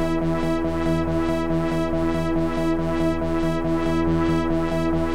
Index of /musicradar/dystopian-drone-samples/Tempo Loops/140bpm
DD_TempoDroneD_140-E.wav